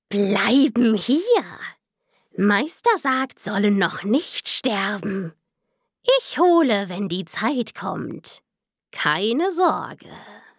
Charakter Fantasy:
Stimmalter: ca. 18-35 Jahre